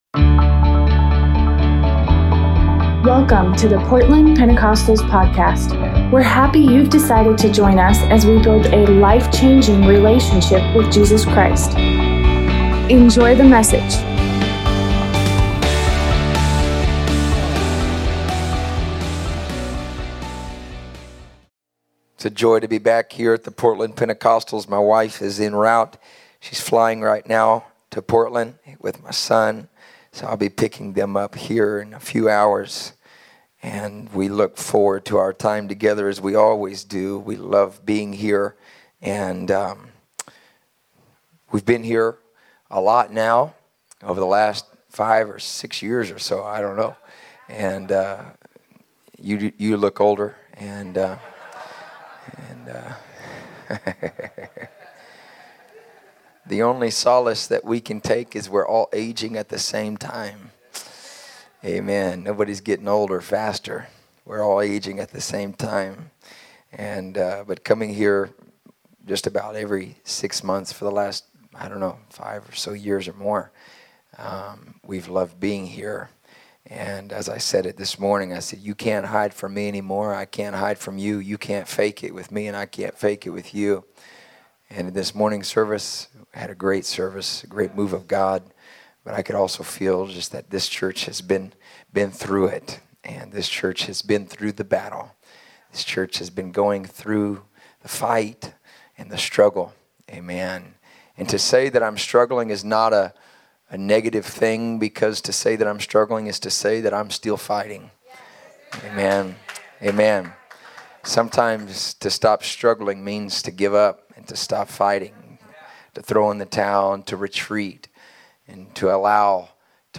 Sunday revival service